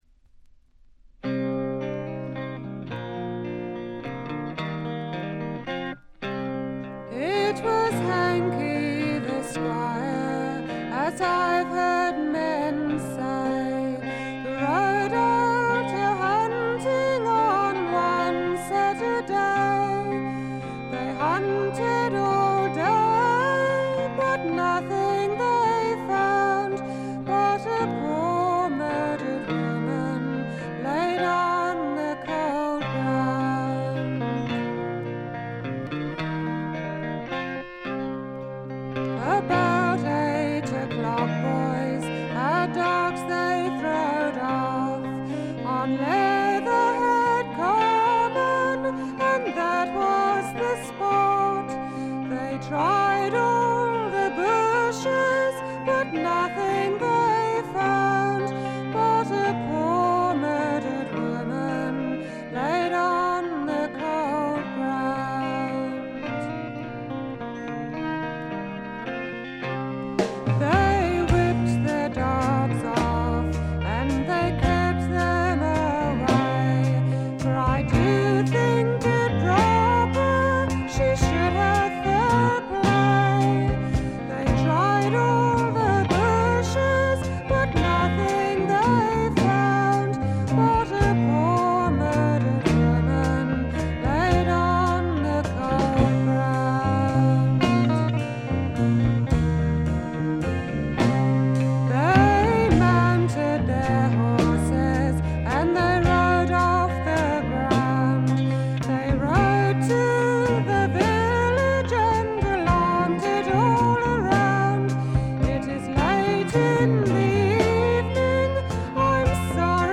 エレクトリック・トラッド最高峰の一枚。
試聴曲は現品からの取り込み音源です。